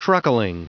Prononciation du mot truckling en anglais (fichier audio)
Prononciation du mot : truckling